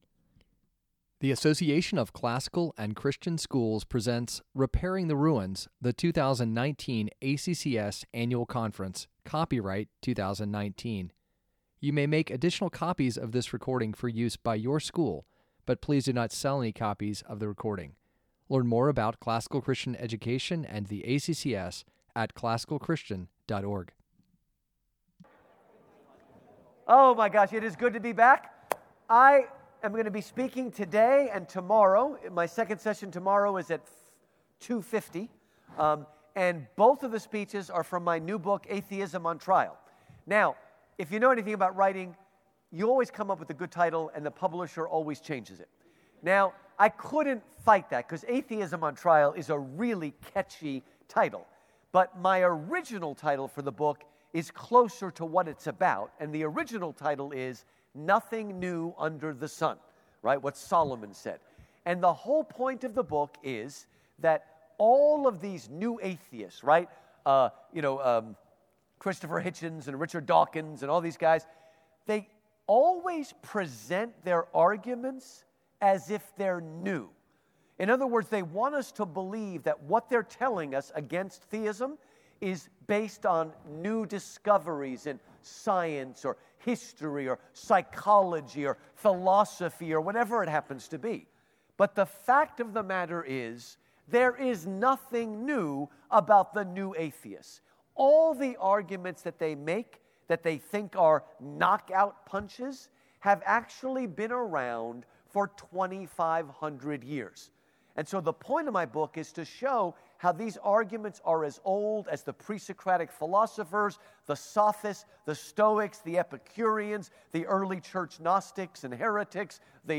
2019 Workshop Talk | 01:01:17 | All Grade Levels, Culture & Faith
Jul 30, 2019 | All Grade Levels, Conference Talks, Culture & Faith, Library, Media_Audio, Workshop Talk | 0 comments